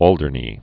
(ôldər-nē)